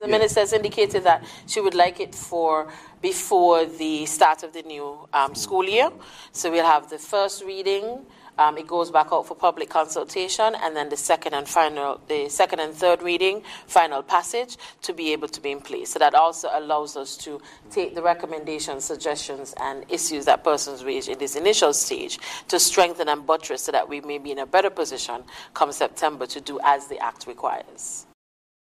Appearing on the Infocus Programme, Ms. Azilla Clarke, Permanent Secretary in the Ministry of Social Development and Gender Affairs shared this information: